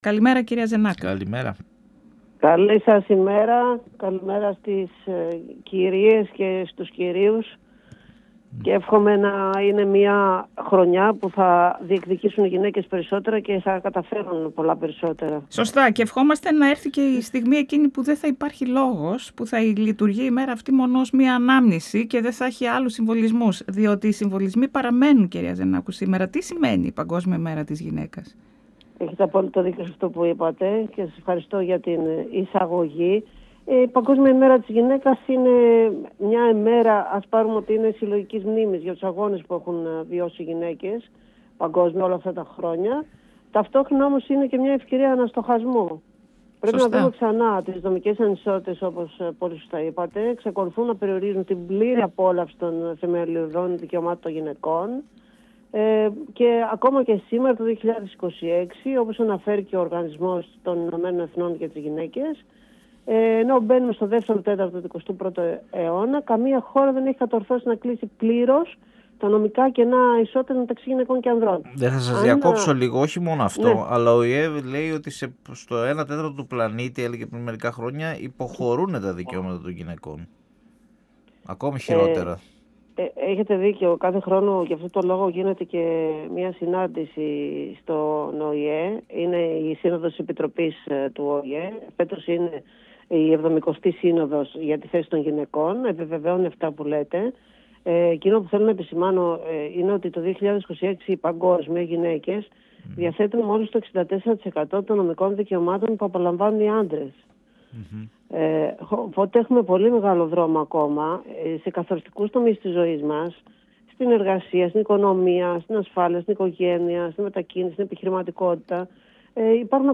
102FM Τα Πιο Ωραια Πρωινα Συνεντεύξεις